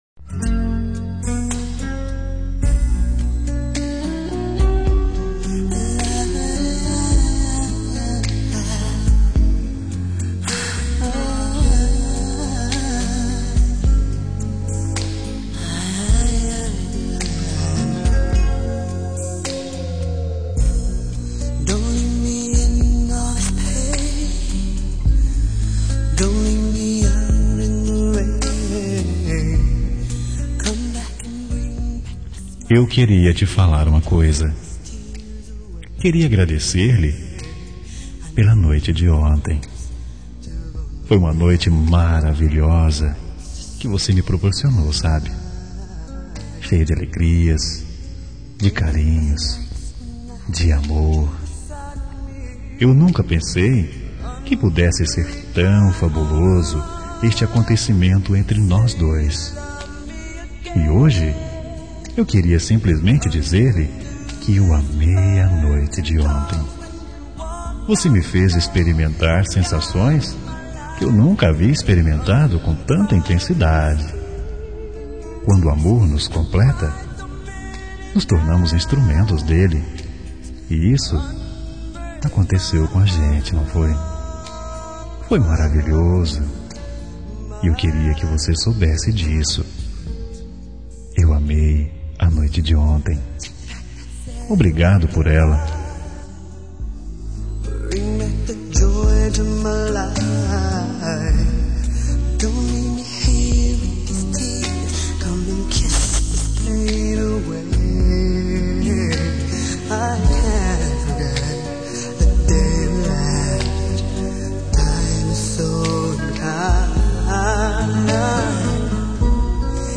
Telemensagem de Agradecimento – Pela Noite – Voz Masculina – Cód: 25